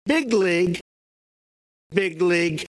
With big league in mind, it’s easier to hear a final /g/ in many of Trump’s utterances. Here’s a sentence from his first debate with Hillary Clinton, then the two instances of big league slightly slowed down: